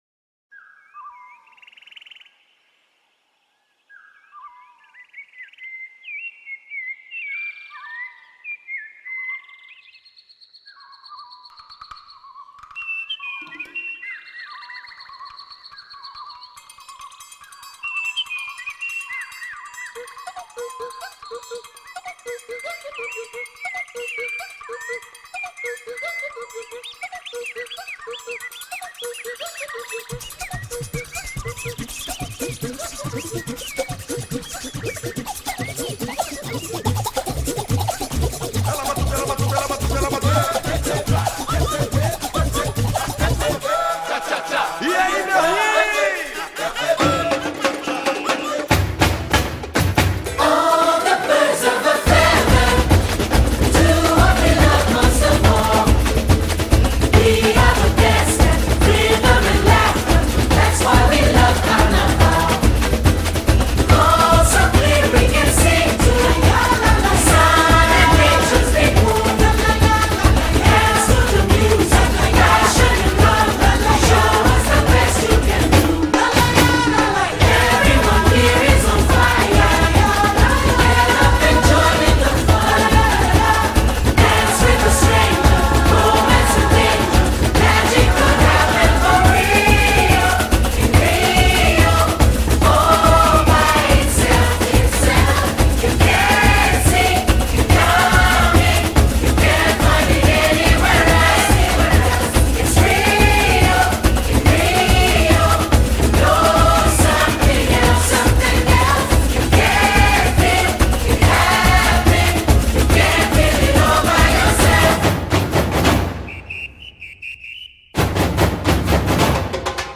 oiseaux.mp3